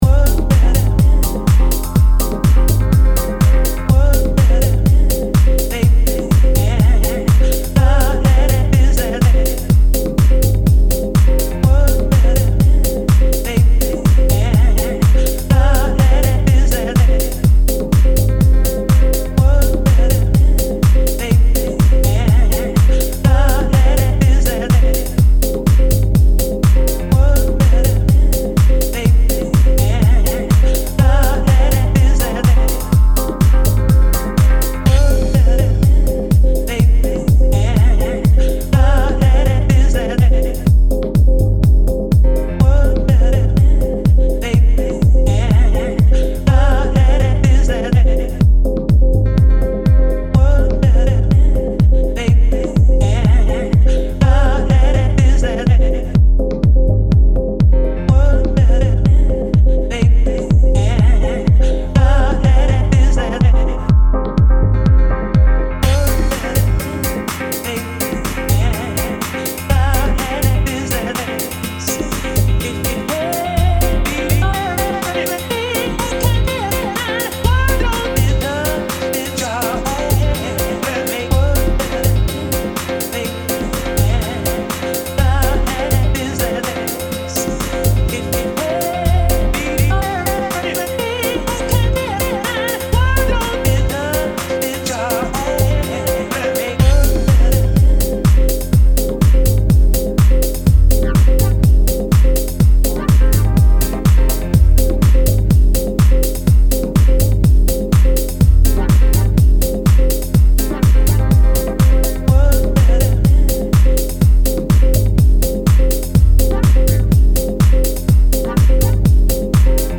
its minimalistic build-up features dynamic grooves.